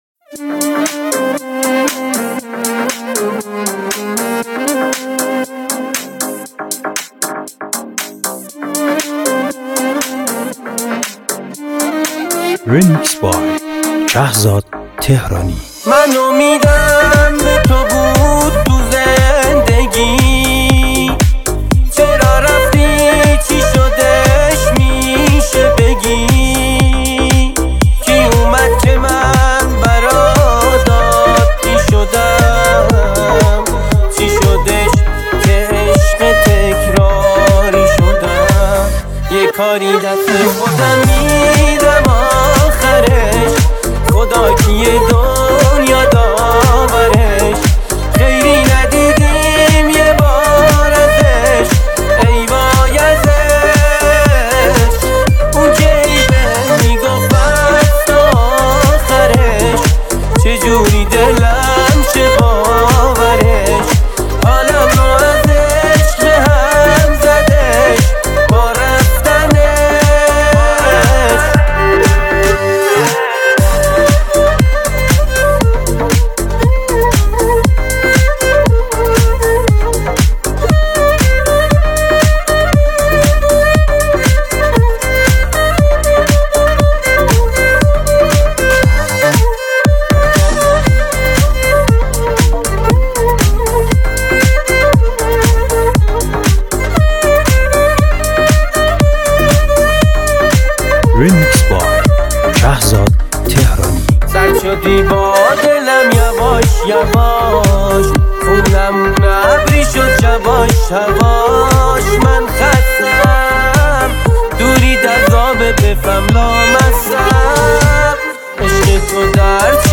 آهنگآهنگ درجه یکدانلوددانلود ریمیکسریمیکسموزیک - عمومی